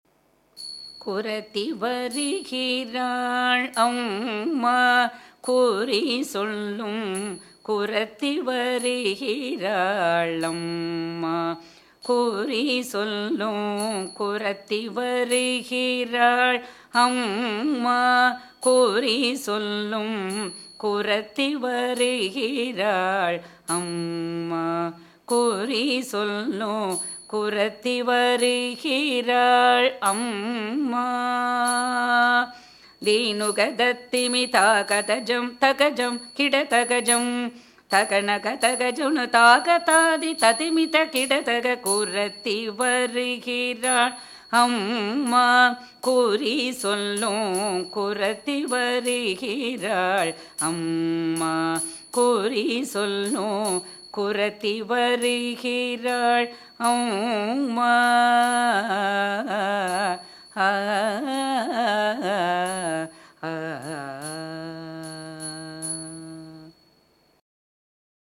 இராகம் : பியாகடை         தாளம் : ஆதி